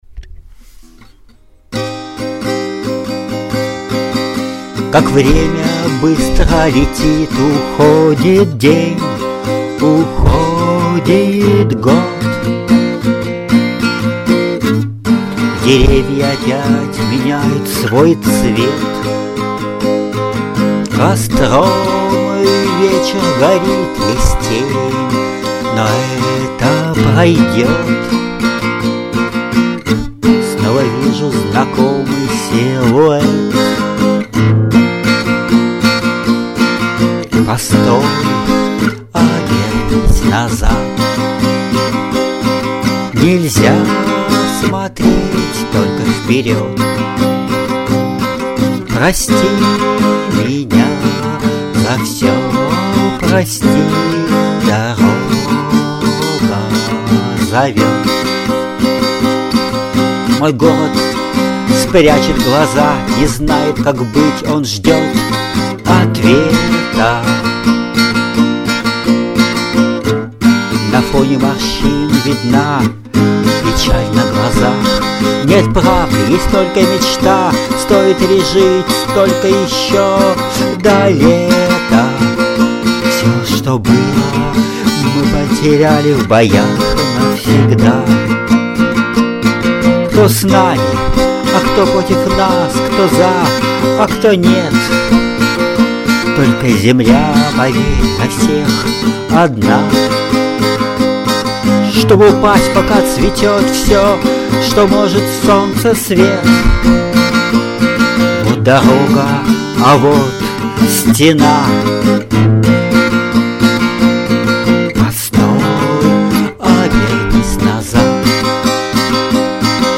кавер